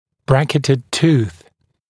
[‘brækɪtəd tuːθ][‘брэкитэд ту:с]зуб с установленным брекетом